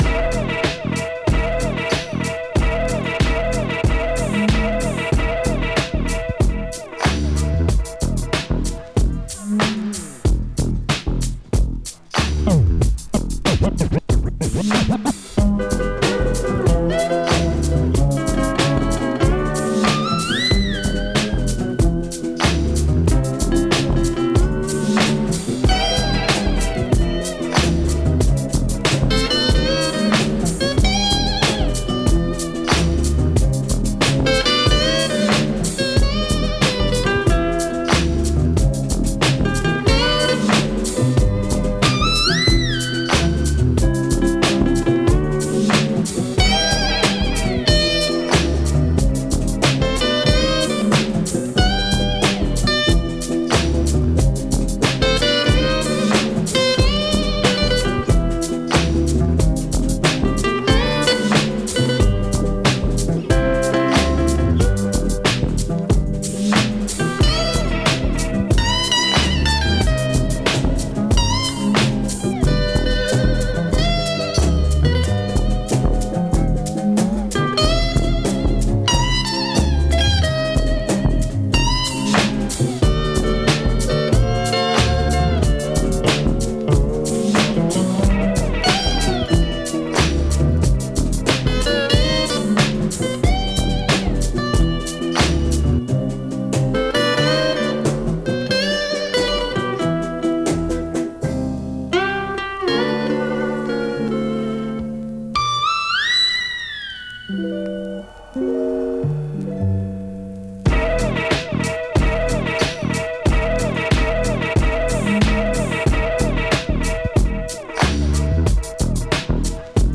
Beats Dance Hip Hop Rap Reggae/Dub